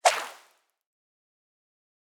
Water arrow trail version 4.wav